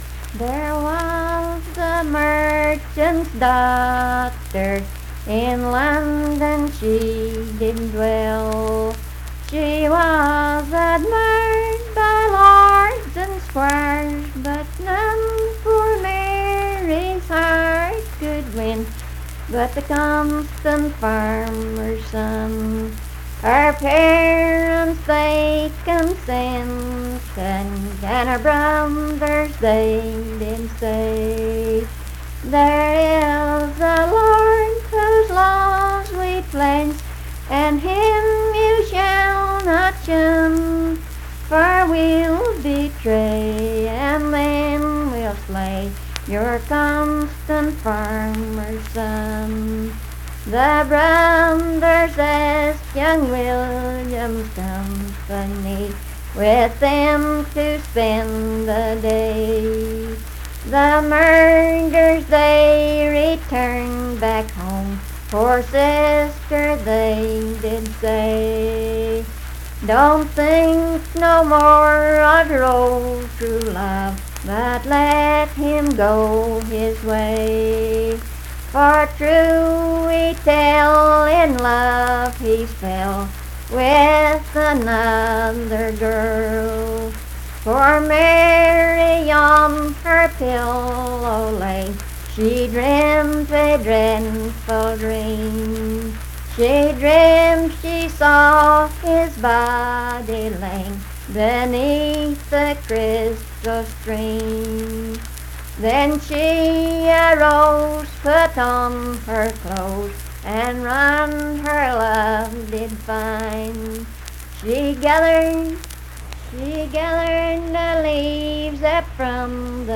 Unaccompanied vocal music
Verse-refrain 8(6w/R).
Performed in Strange Creek, Braxton, WV.
Voice (sung)